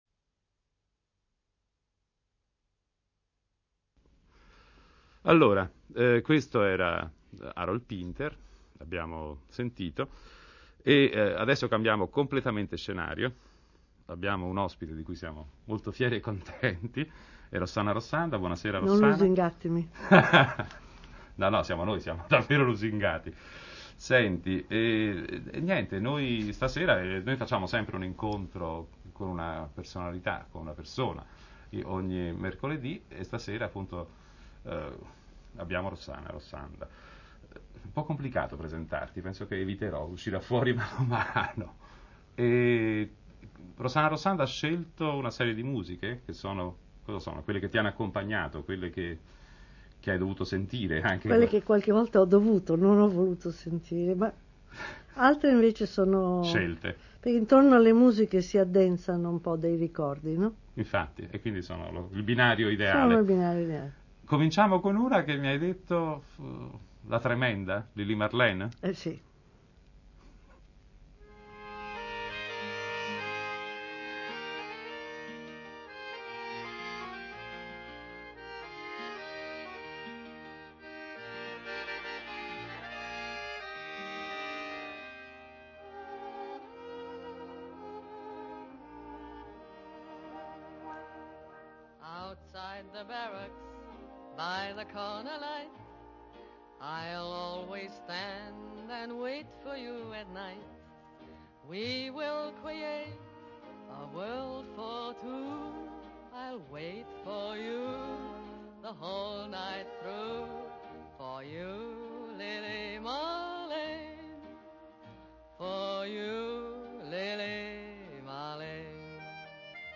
La musica di Rossana Rossanda. Intervista